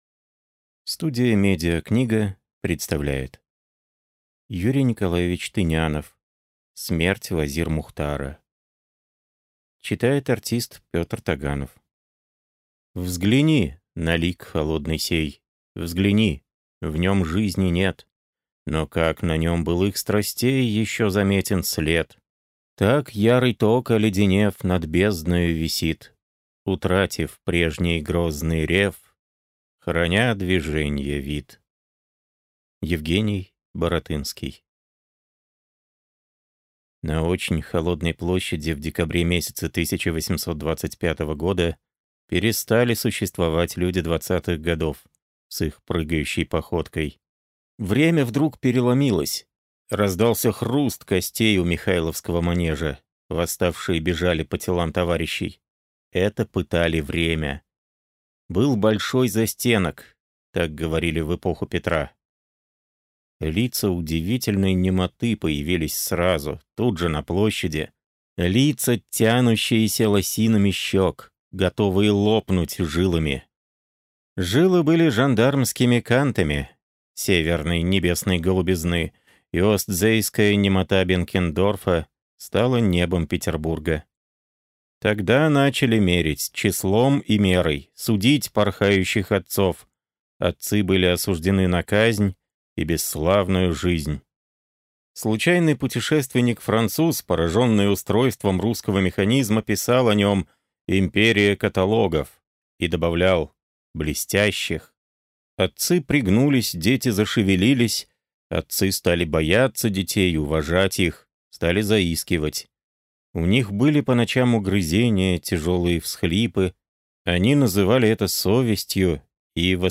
Аудиокнига Смерть Вазир-Мухтара | Библиотека аудиокниг